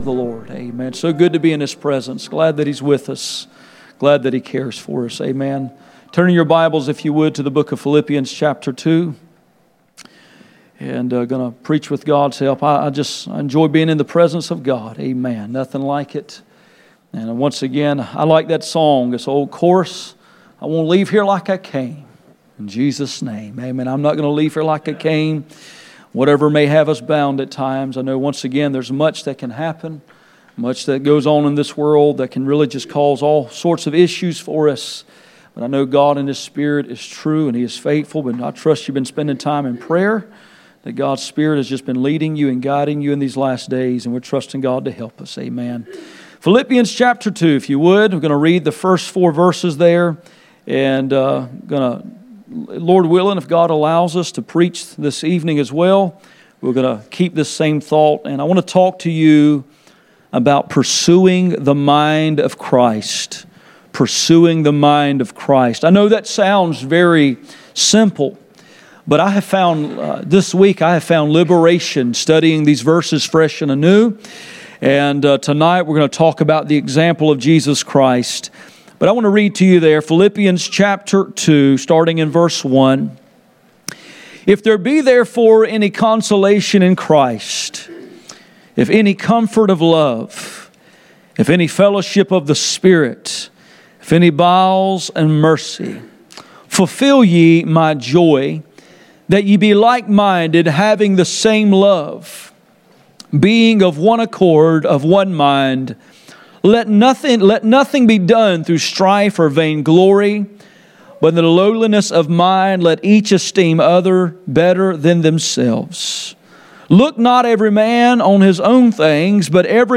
None Passage: Philippians 2:1-4 Service Type: Sunday Morning %todo_render% « Unacceptable Worship Pursuing the mind of Christ